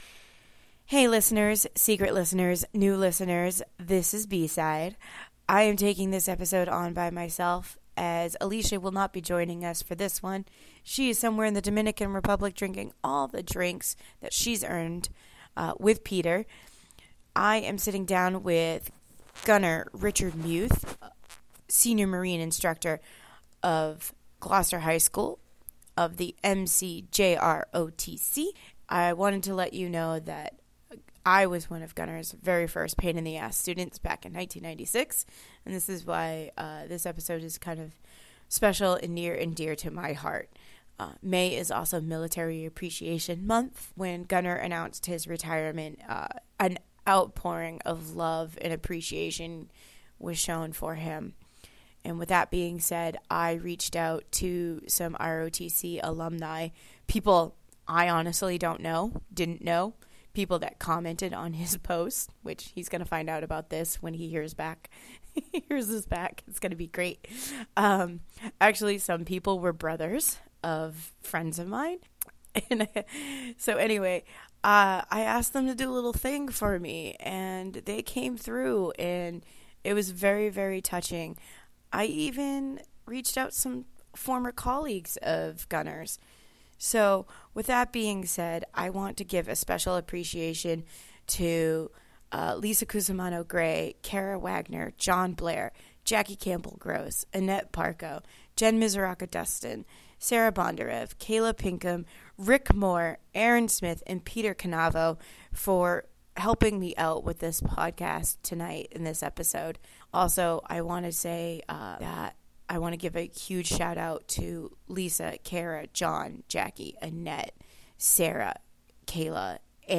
Episode 92-(on location)